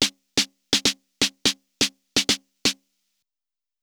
SNARE001_DISCO_125_X_SC3.wav